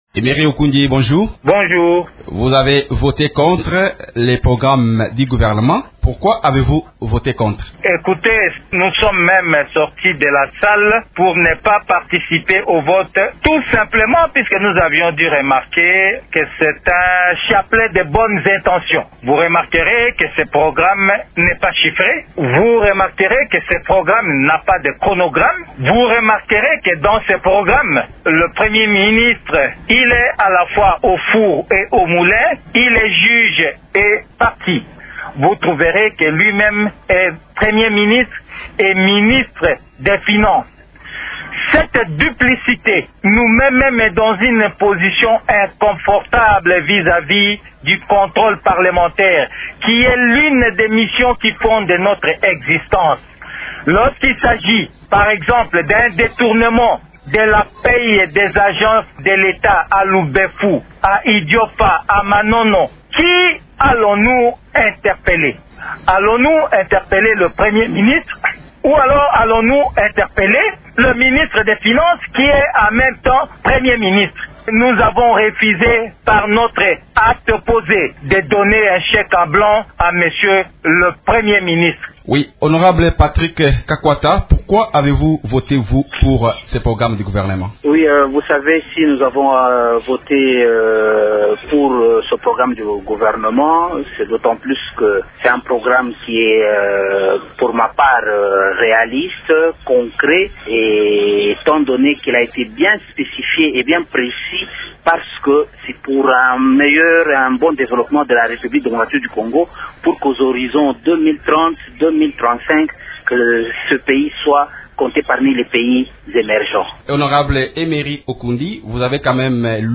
Les députés Emery Ukundji, de l’opposition, et Patrick Kakwata, de la majorité, sont les invités de Radio Okapi ce jeudi.